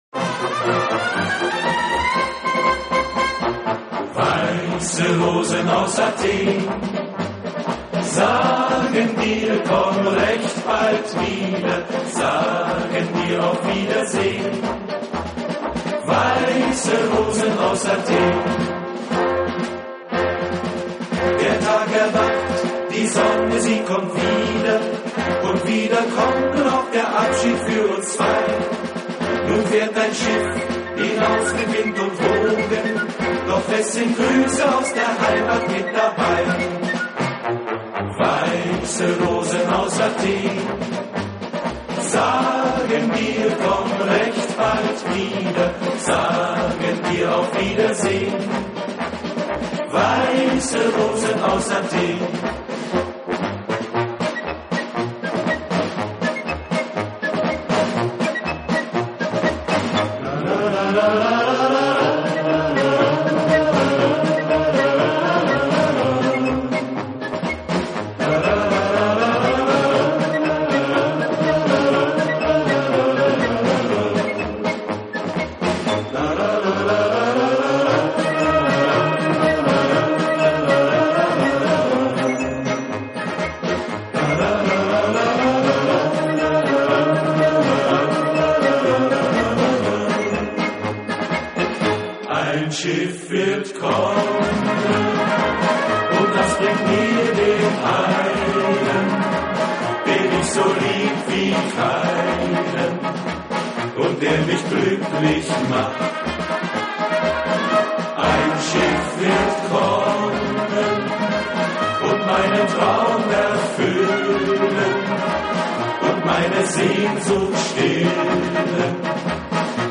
专辑由一组男声多声部合 唱曲。
今天的专辑大概算德国风格的合唱，唱的是 前二三十年的流行歌曲。 专辑的曲目是集成曲形式。